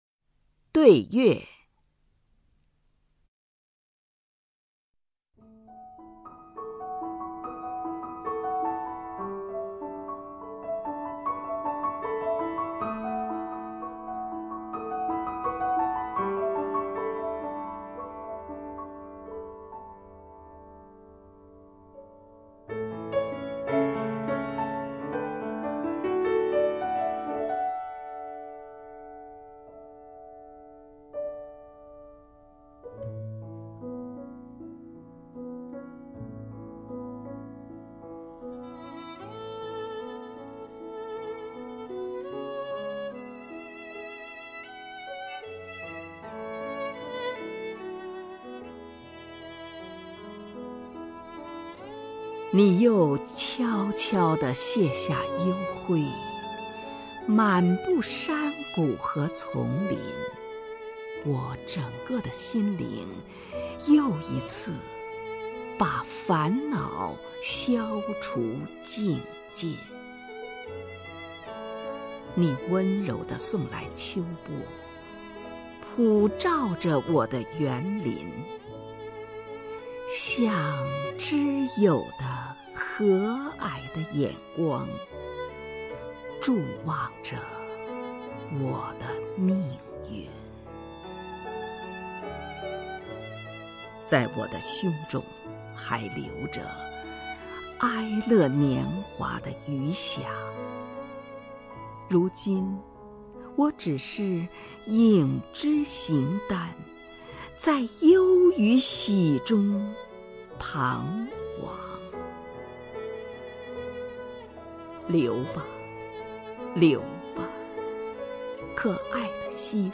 虹云朗诵：《对月》(（德）约翰·沃尔夫冈·冯·歌德)
名家朗诵欣赏 虹云 目录